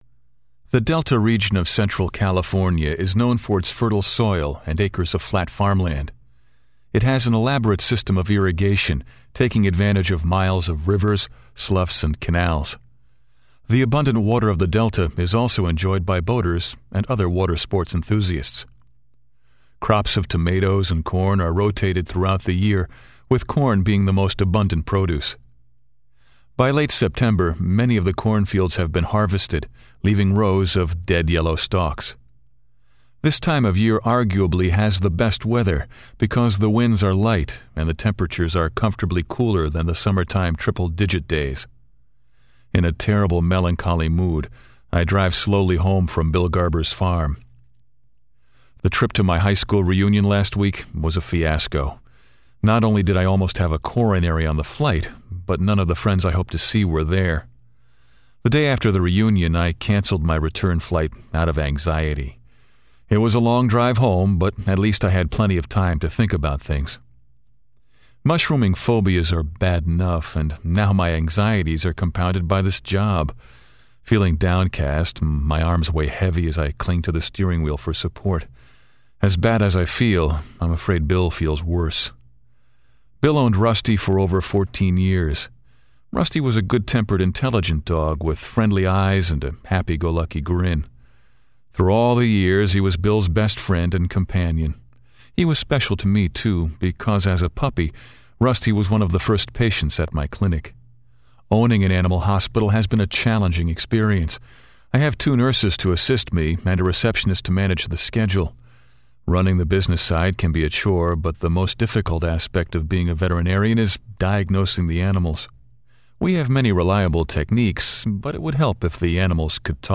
Audio Book Demos
Male Voice Over Talent